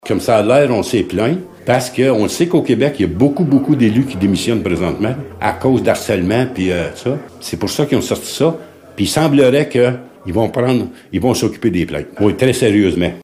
Le maire, Laurent Fortin, commente :